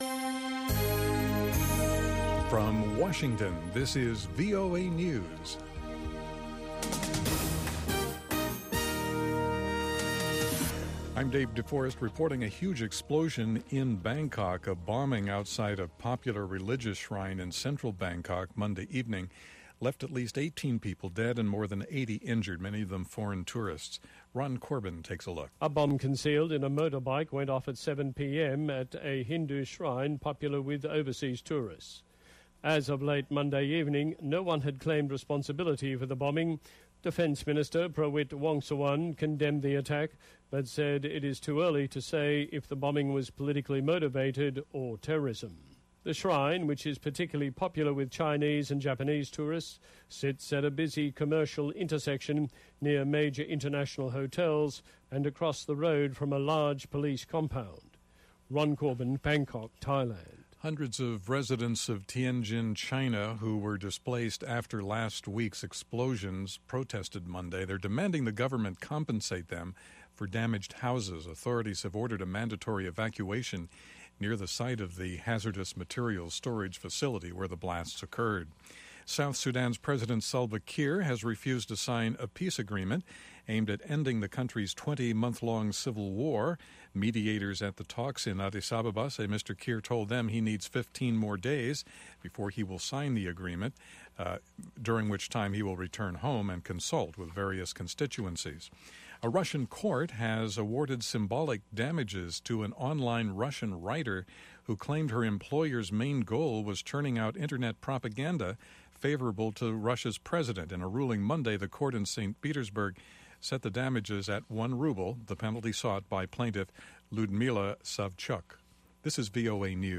N’dombolo to Benga to African Hip Hop
pan-African music